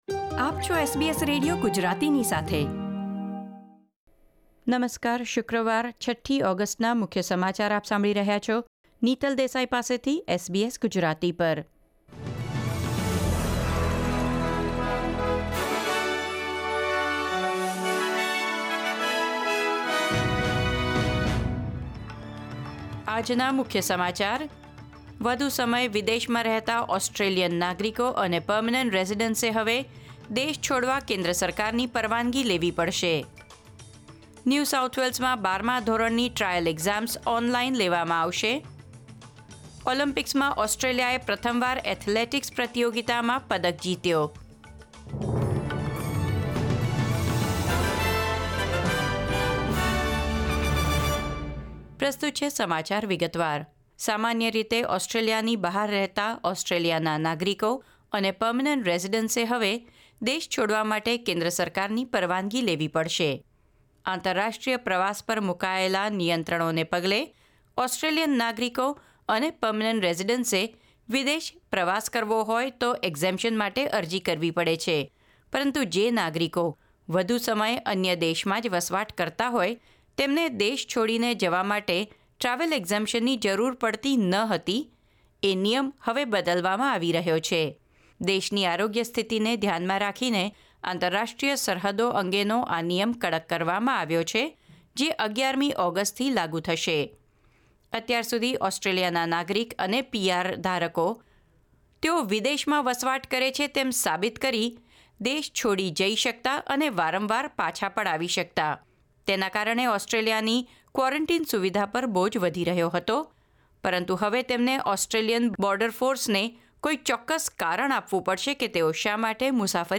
SBS Gujarati News Bulletin 6 August 2021